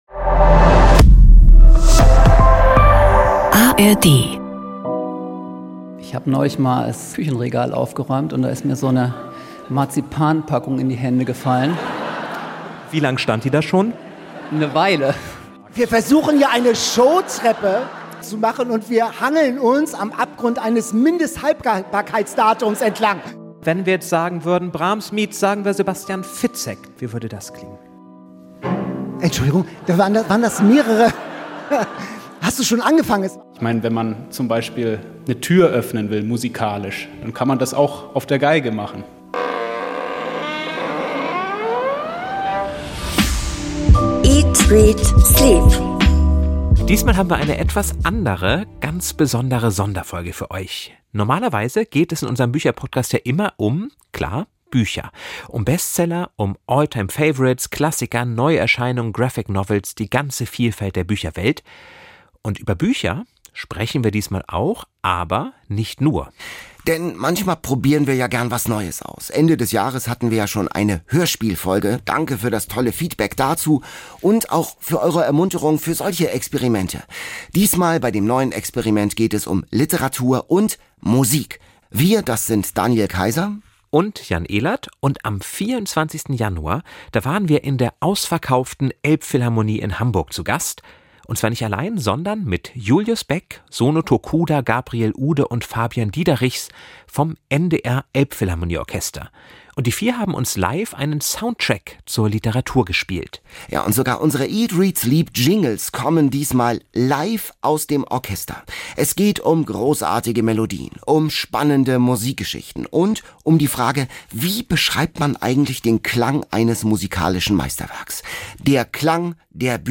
Welche Musik ist der passende Soundtrack zu einem richtig guten Buch? Gemeinsam mit Musiker*innen des NDR Elbphilharmonie Orchesters
einen Abend lang das Zusammenspiel von Wort und Klang